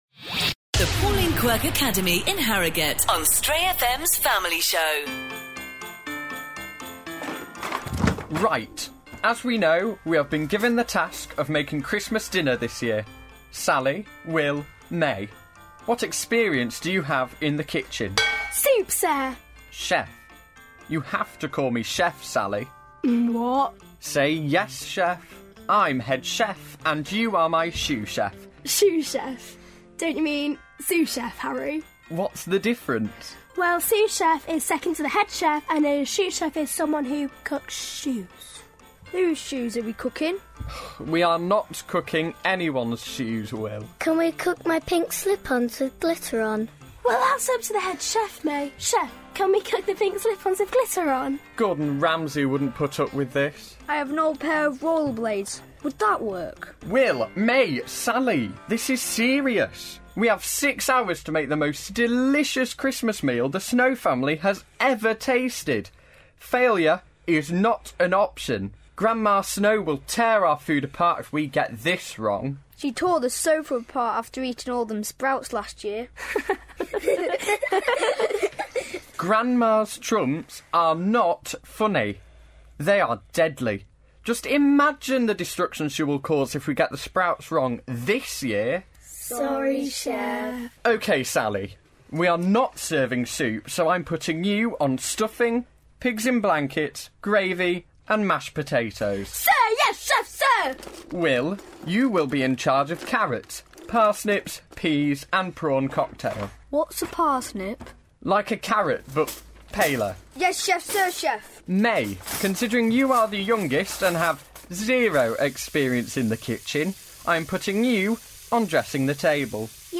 The Pauline Quirke Academy in Harrogate are performing a radio drama for you tonight... what happens when you put children in charge of turkey and all the trimmings!